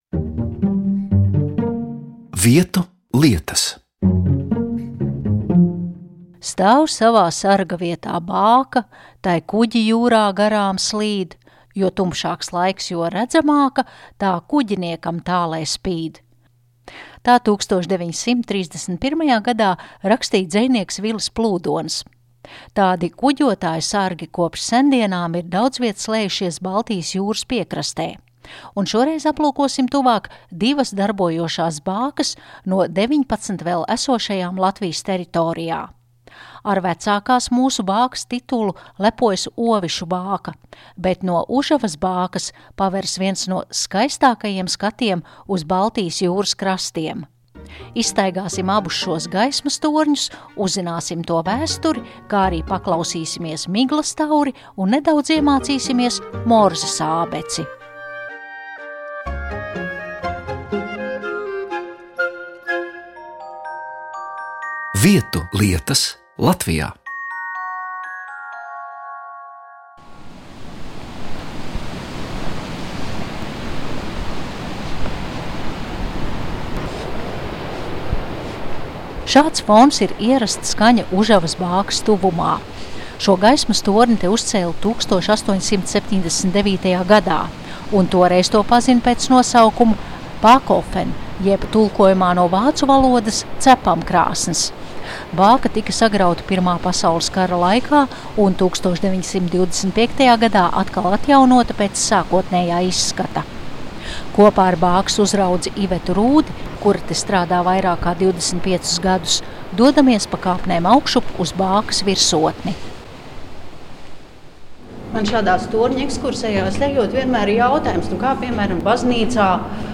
Ar vecākas mūsu bākas titulu lepojas Ovišu bāka, bet no Užavas bākas paveras viens no skaistākajiem skatiem uz Baltijas jūras krastiem. Izstaigājam abus šos gaismas torņus un uzzinām to vēsturi, kā arī paklausāmies miglas tauri un nedaudz apgūstam arī Morzes ābeci.